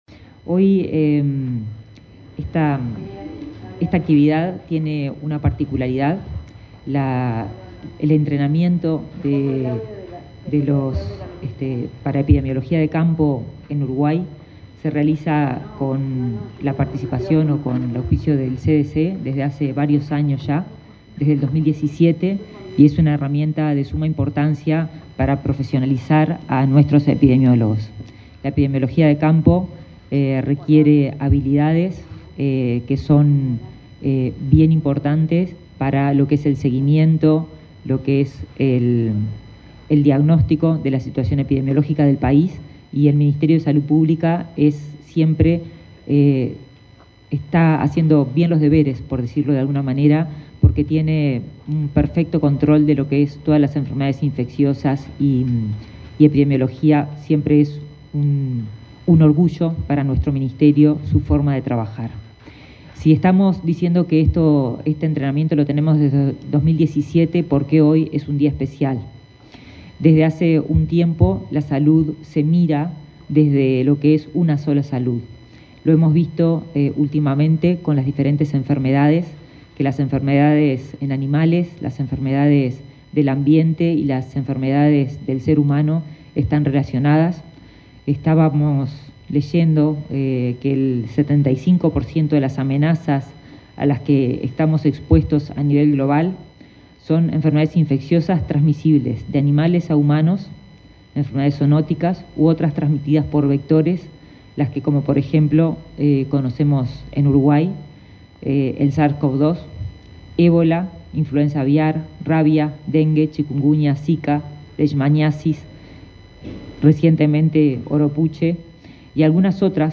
Palabras de autoridades en acto en el MSP
La ministra de Salud Pública, Karina Rando; su par de Ambiente, Robert Bouvier, y el titular interino de Ganadería, Ignacio Buffa, participaron, este